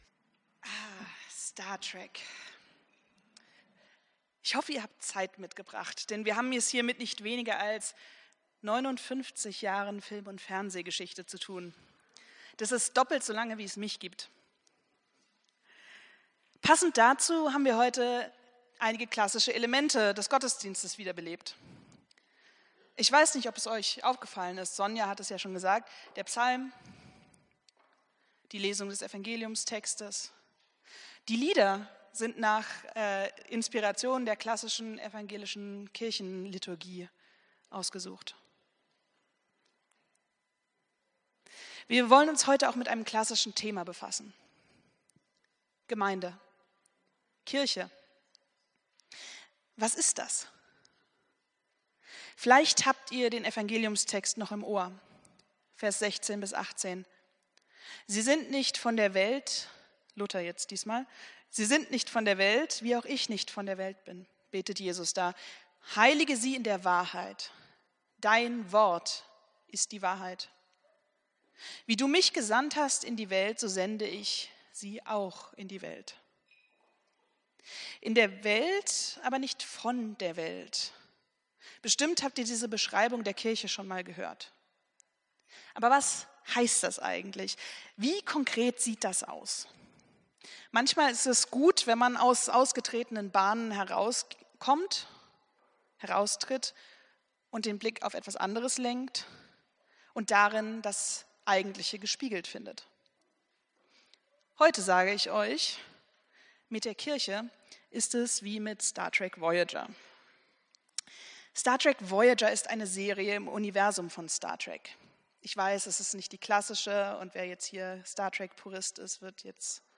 Predigt vom 18.05.2025